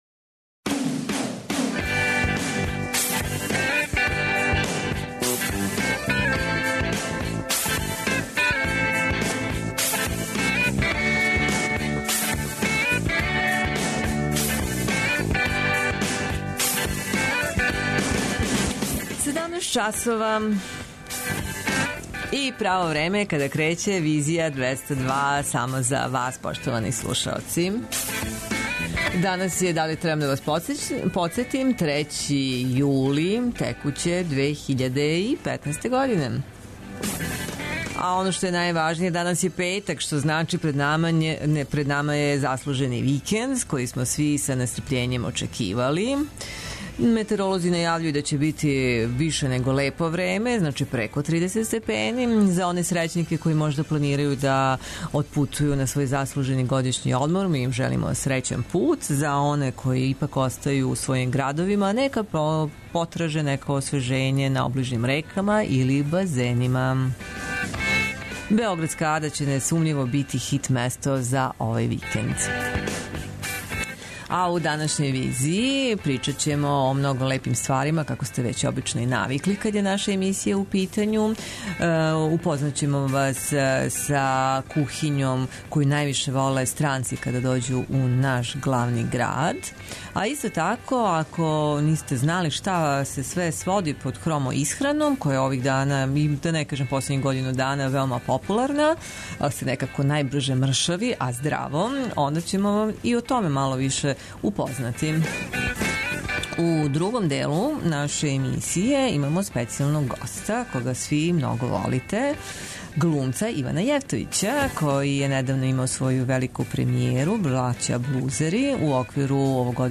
Социо-културолошки магазин, који прати савремене друштвене феномене.